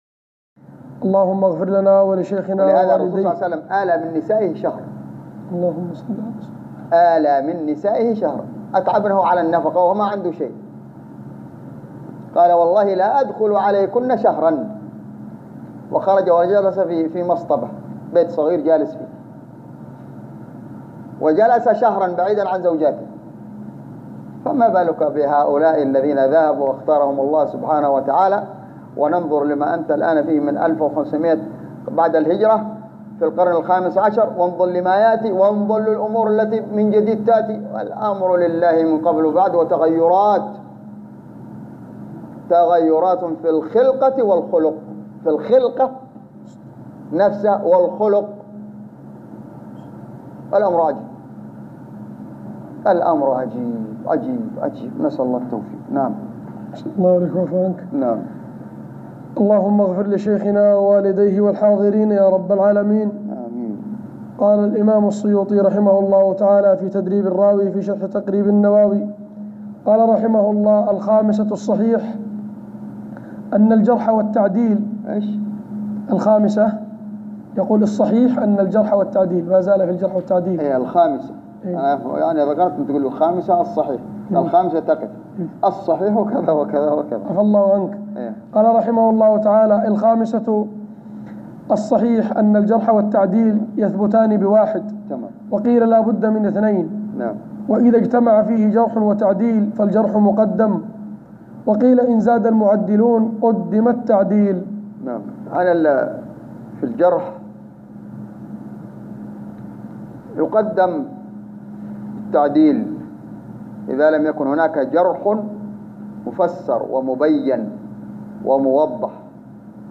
تسجيل لدروس شرح كتاب تدريب الراوي شرح تقريب النواوي